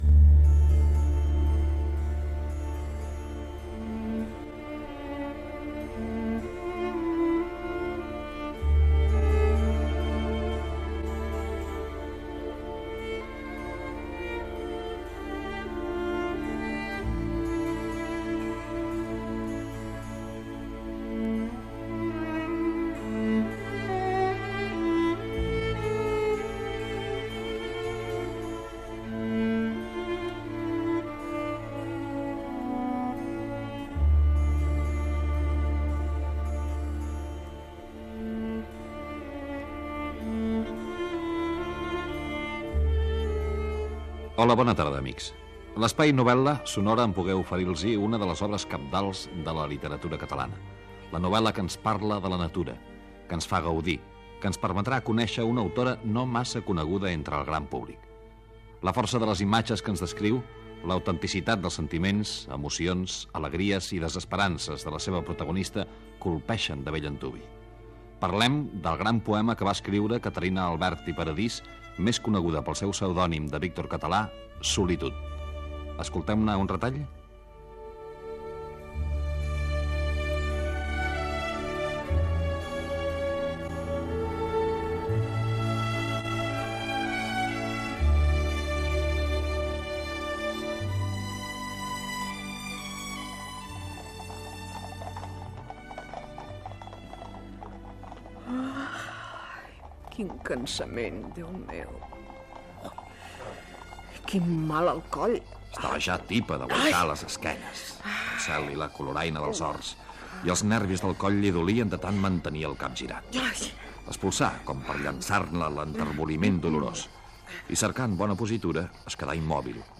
fragment de l'adaptació radiofònica
Gènere radiofònic Ficció